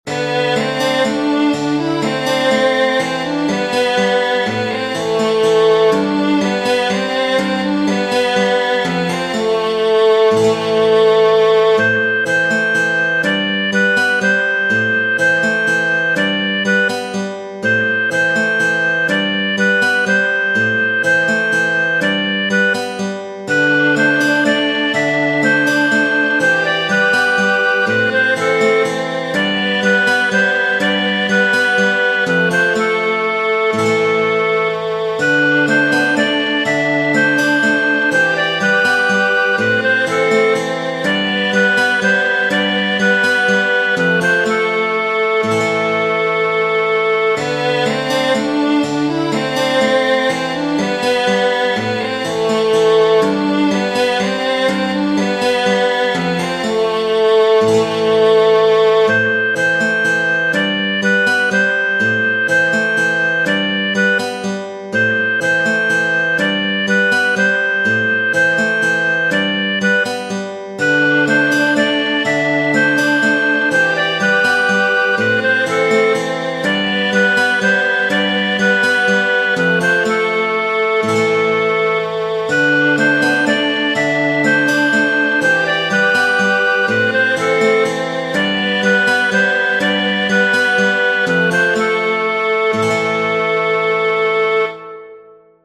Tradizionale Genere: Folk "Yar Ko Parag" (in italiano: cara, amo la tua magrezza) è una canzone d'amore tradizionale dell'Armenia.